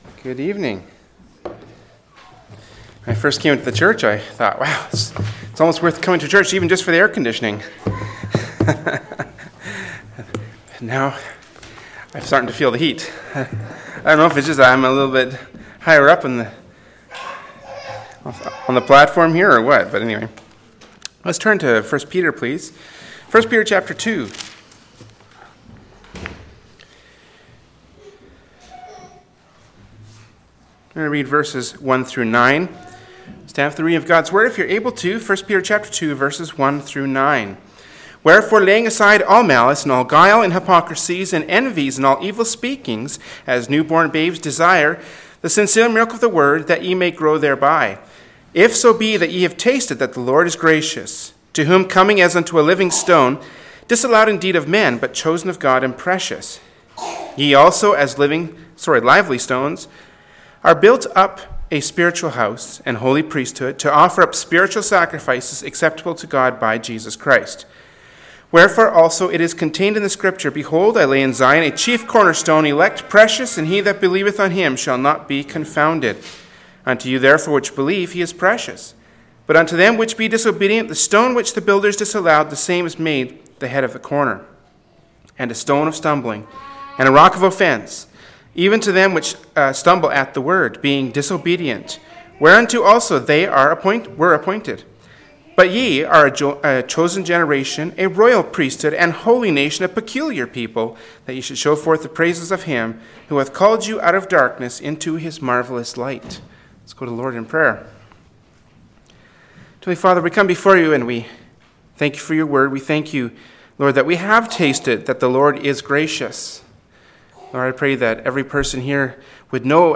“1st Peter 2:1-9” from Wednesday Evening Service by Berean Baptist Church.